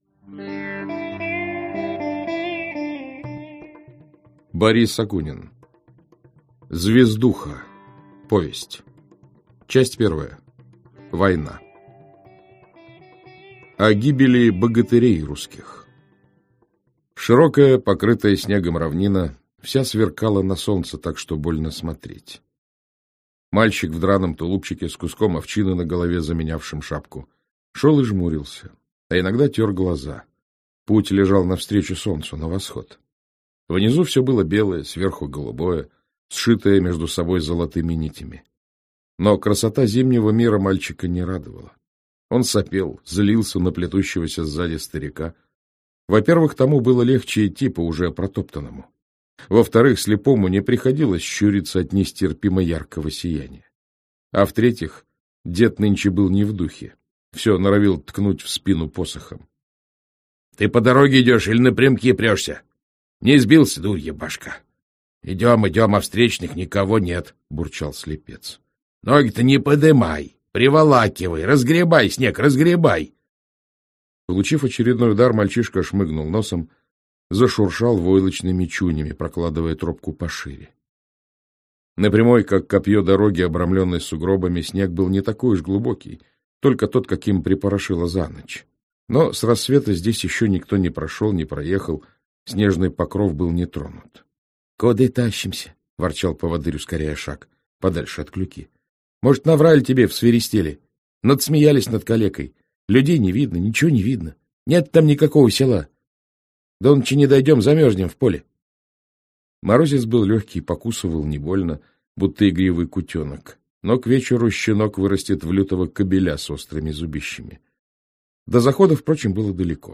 Аудиокнига Бох и Шельма (сборник) - купить, скачать и слушать онлайн | КнигоПоиск